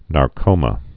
(när-kōmə)